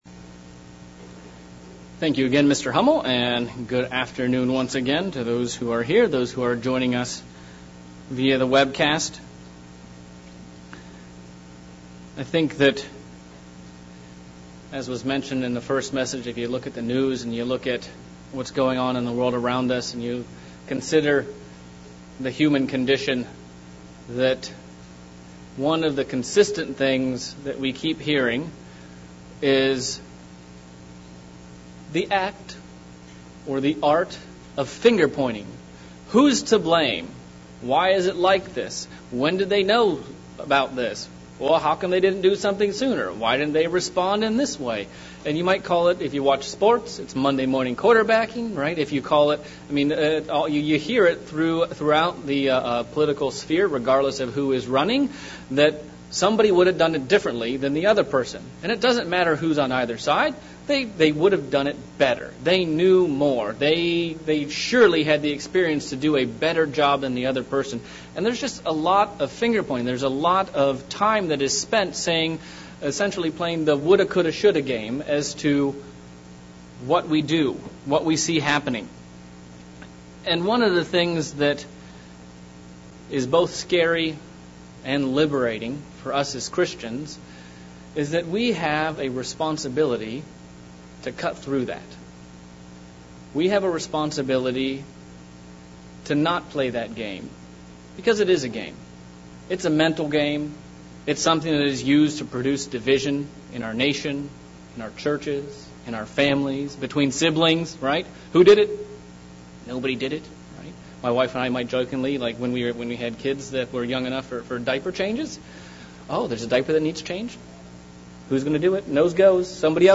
Sermon examinng the fight we've been called to fight and 3 tools to help us along the way.